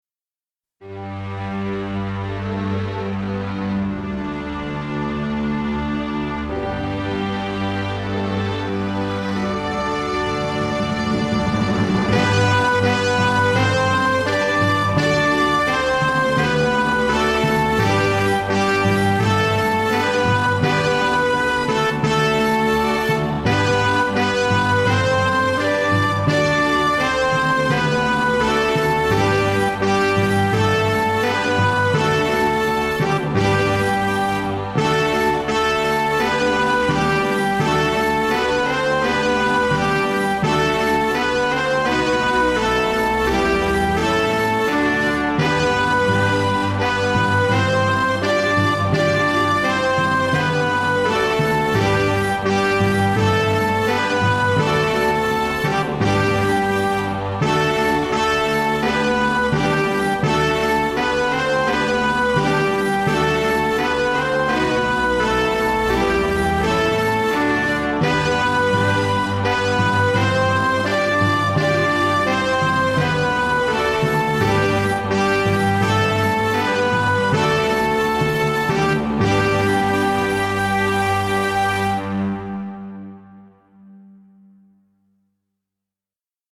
Vredeslied: heel langzaam